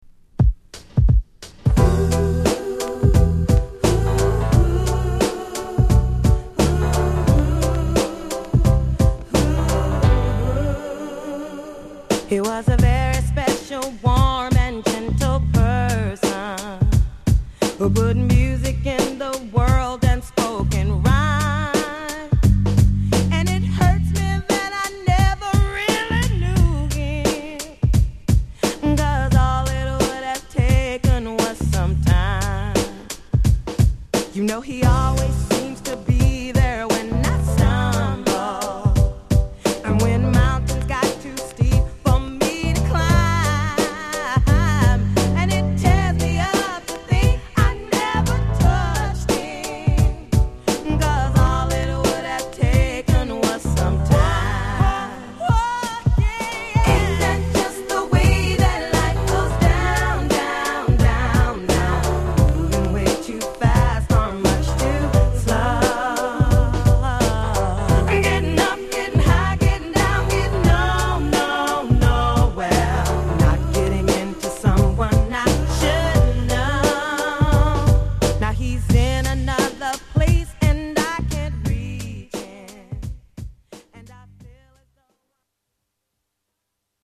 Genre: #R&B
Sub Genre: #1990s